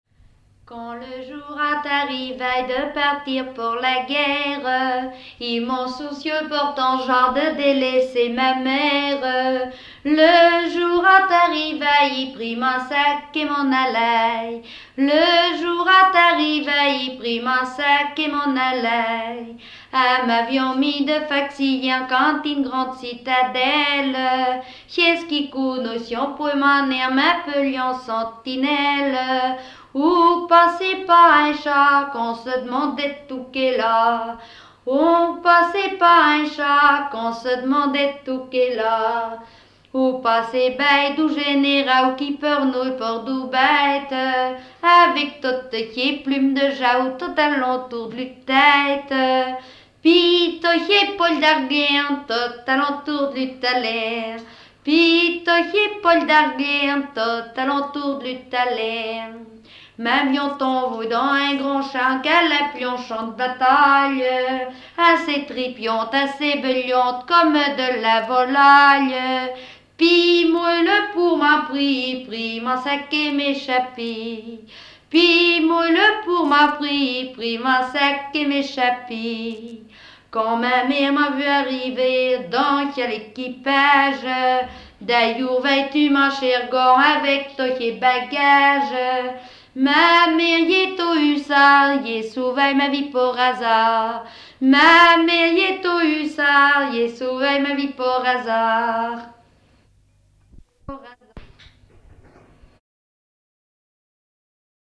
Genre strophique
Chansons en compagnie de chanteurs
Pièce musicale inédite